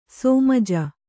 ♪ sōmaja